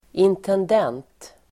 Uttal: [intend'en:t]